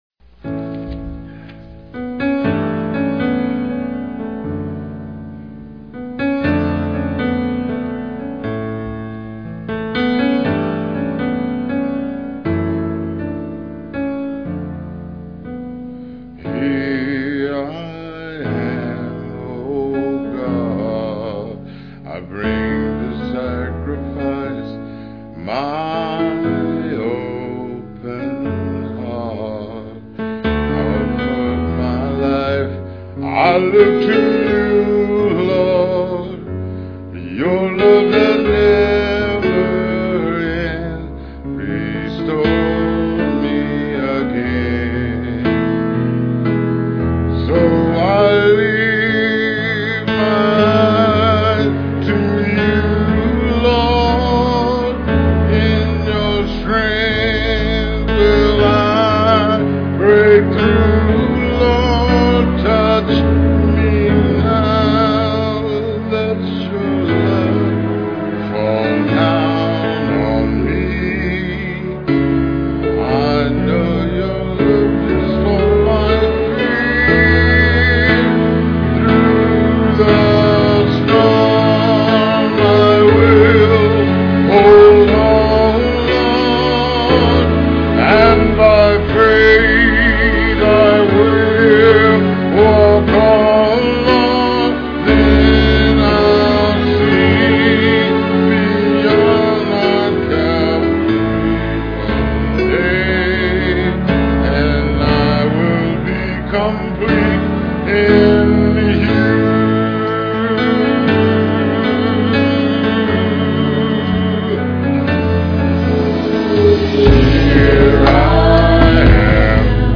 ABC choir.